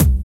Index of /90_sSampleCDs/Roland L-CD701/DRM_Analog Drums/KIK_Analog Kicks
KIK BOING06L.wav